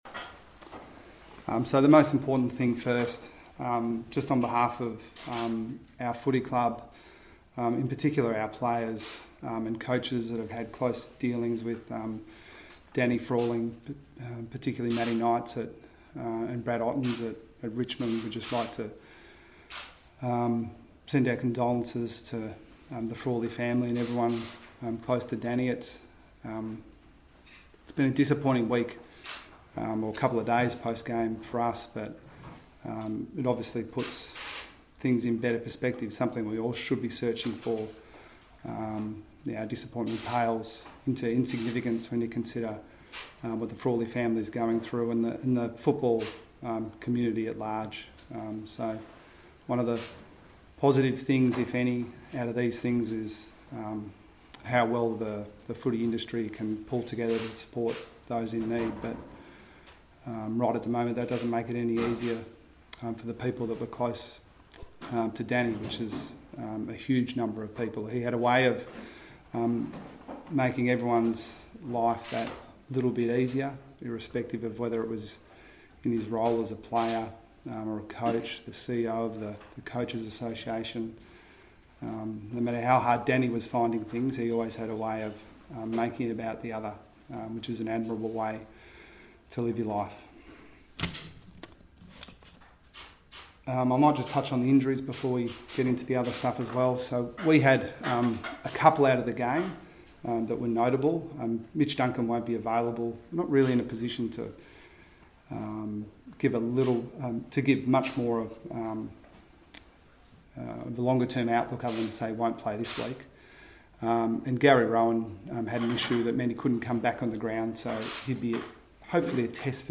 Geelong coach Chris Scott faced the media ahead of Friday night's semi-final meeting with West Coast.